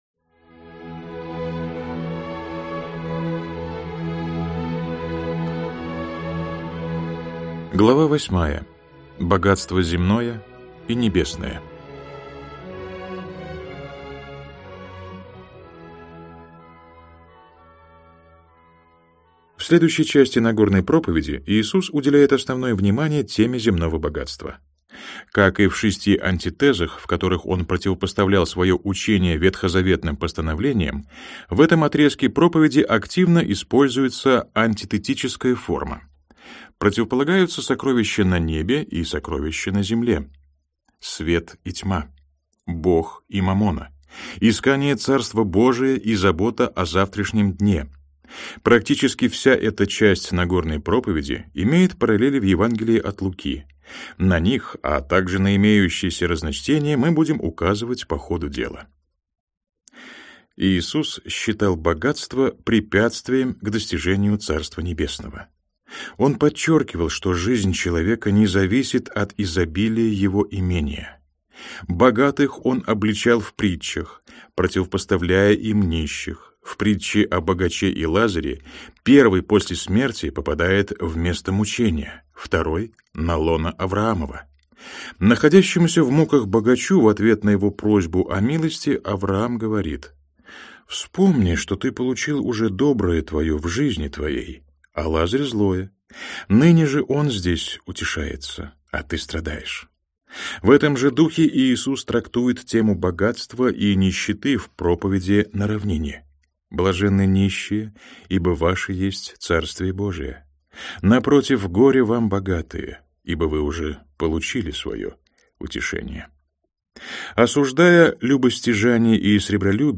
Аудиокнига Иисус Христос. Жизнь и учение. Книга II Нагорная проповедь.